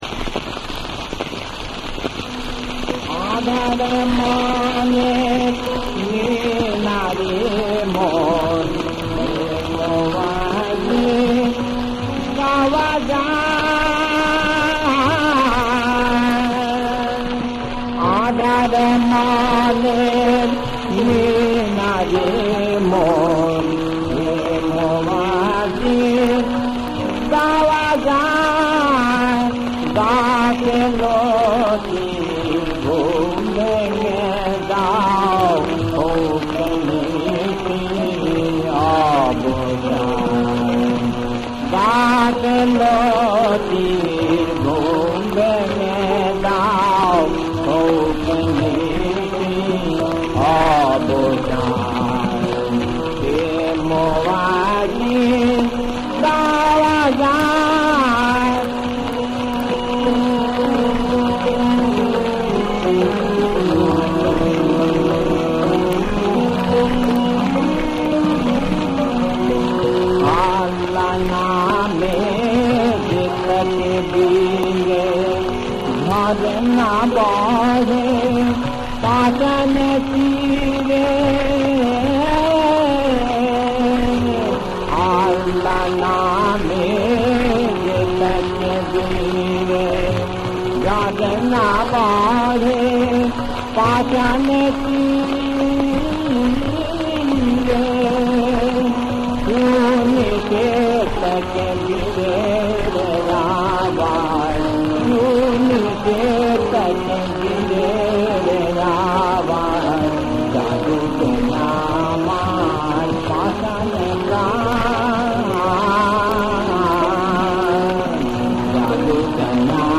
• বিষয়াঙ্গ: ধর্মসঙ্গীত। ইসলামি গান।
• তাল: কাহারবা